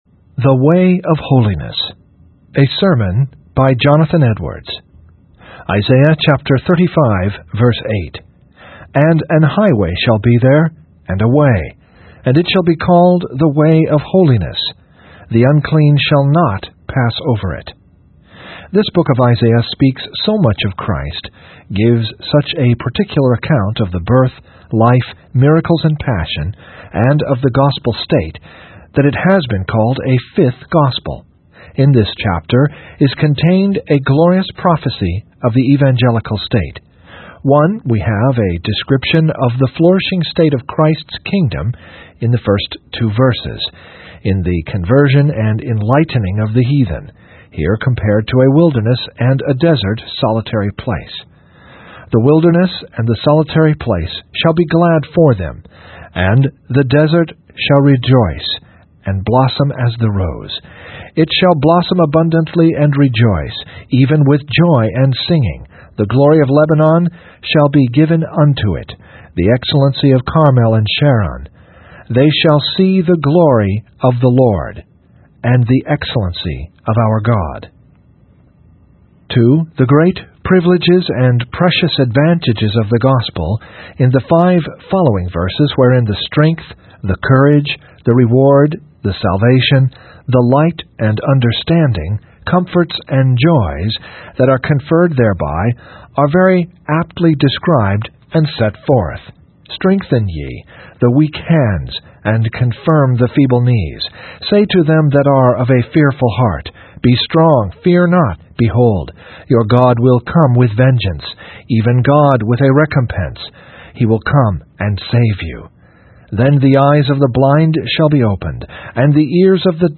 The Way of Holiness (Reading) by Jonathan Edwards | SermonIndex